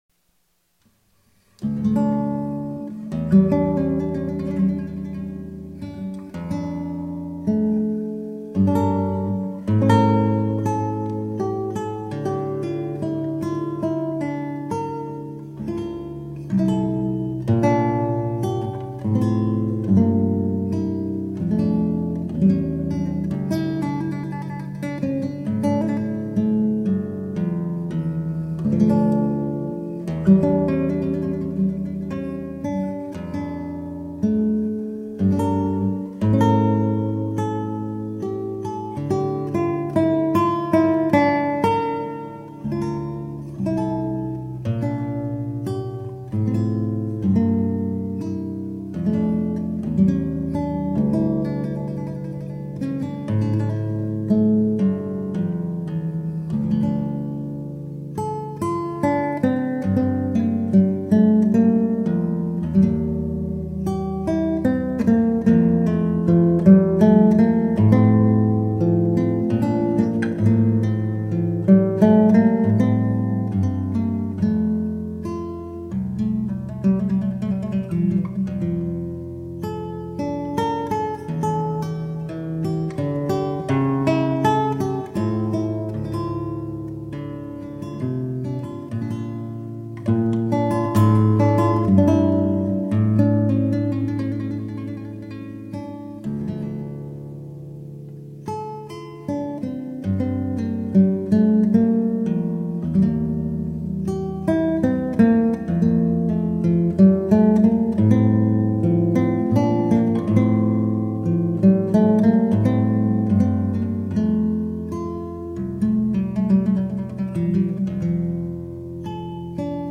Classical, Baroque, Instrumental, Lute